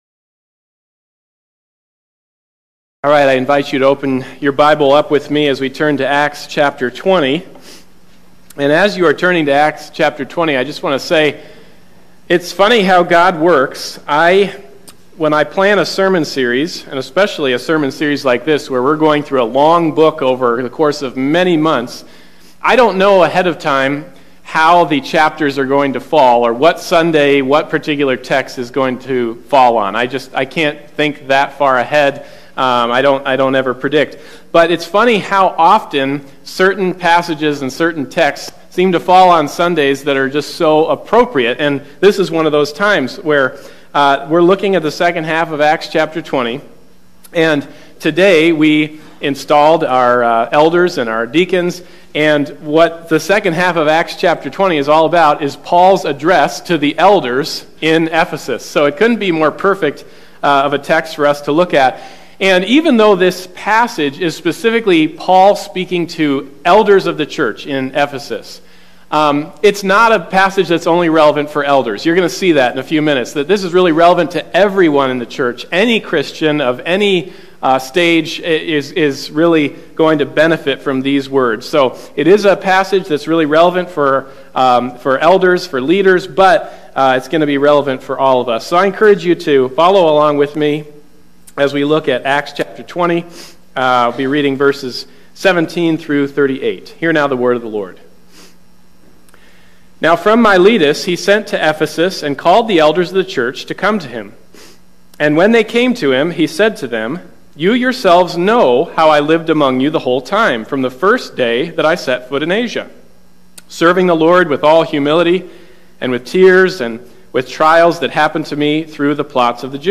Acts 20:17-38 Service Type: Sunday Morning Service « Loving the Church Paul’s Bravery or Paul’s Blunder?